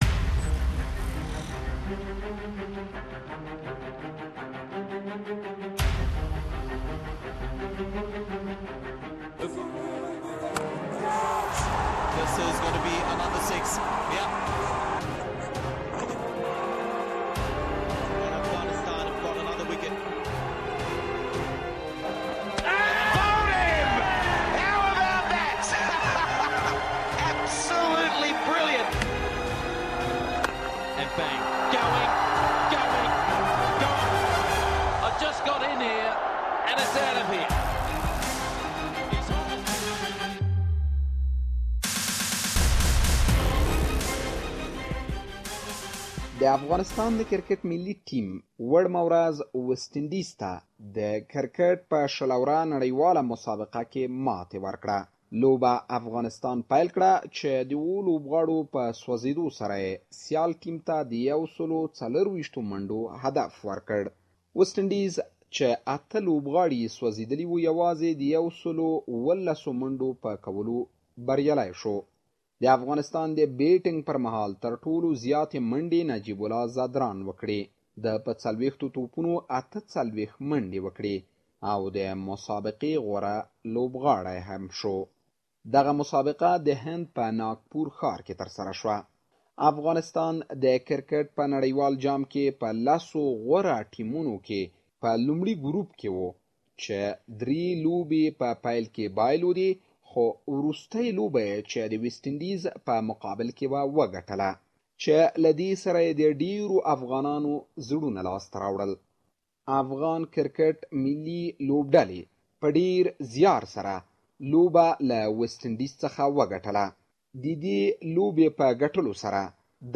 Here are some voices from the street of Kabul in support of their team.